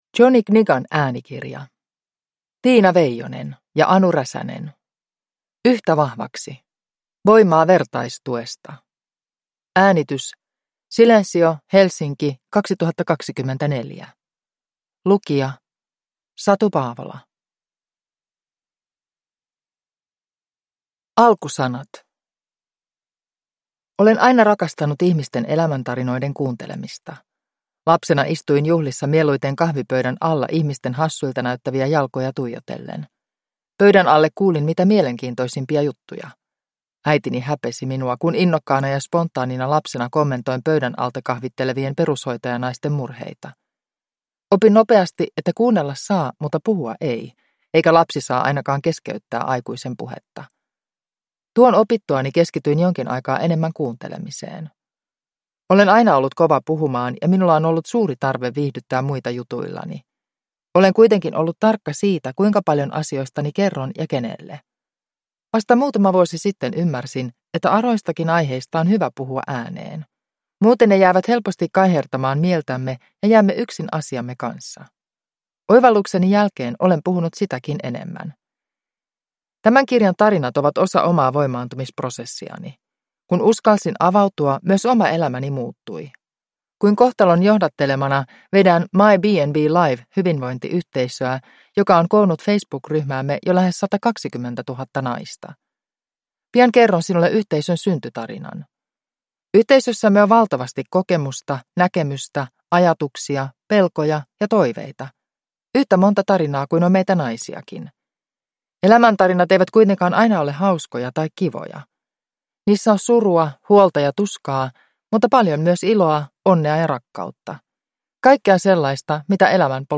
Yhtä vahvaksi – Ljudbok